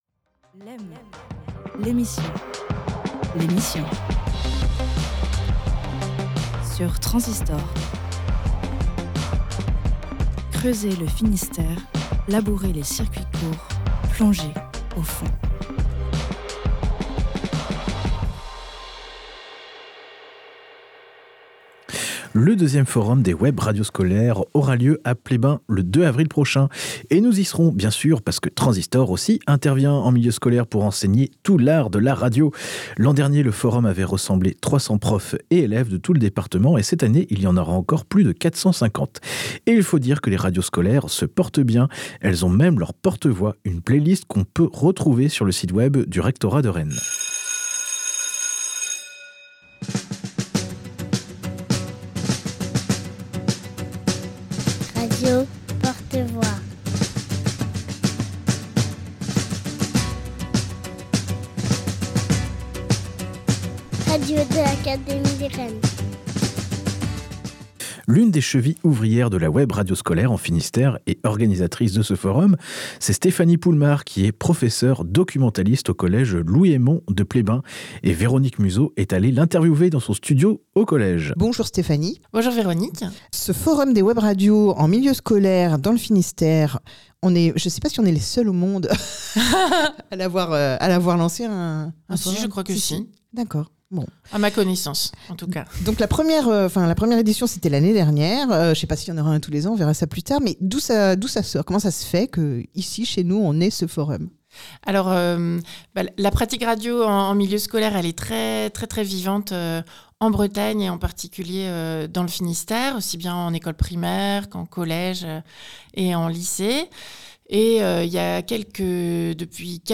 MICR FORUM WEBRADIO.mp3